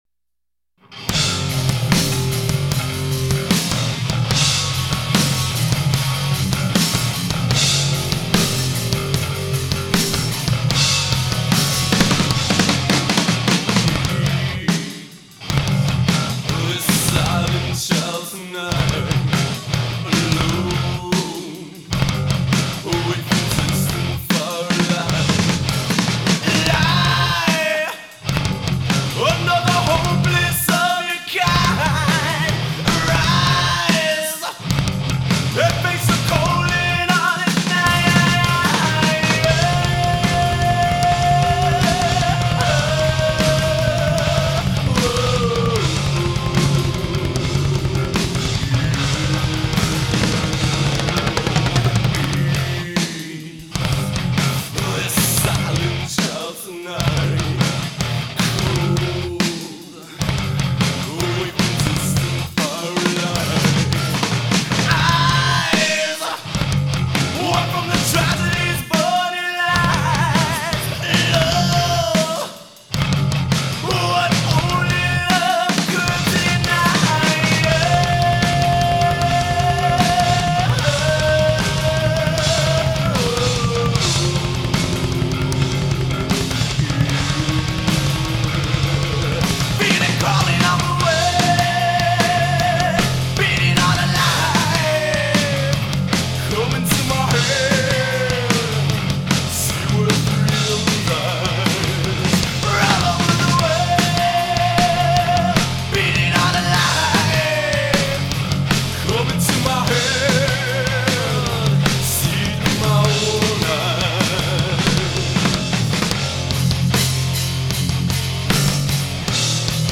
Studio recordings
Bass
Music Unlimited Studios, Bronx, NY